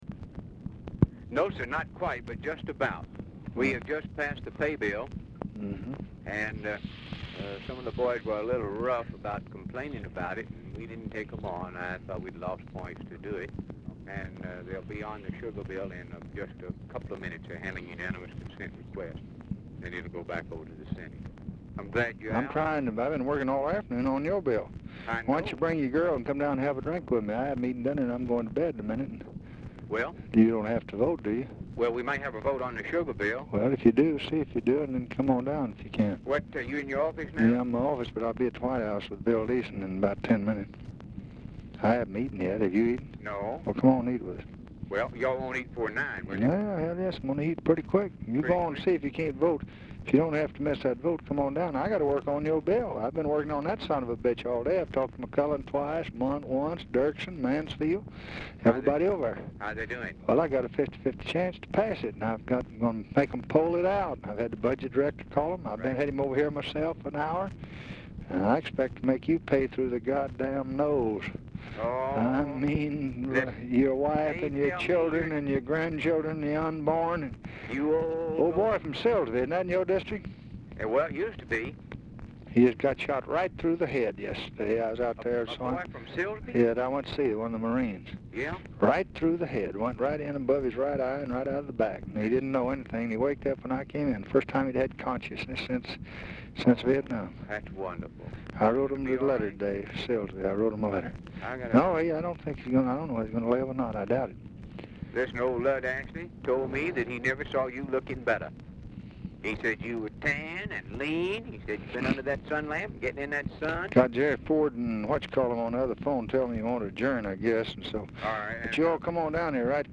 Oval Office or unknown location
RECORDING STARTS AFTER CONVERSATION HAS BEGUN
Telephone conversation
Dictation belt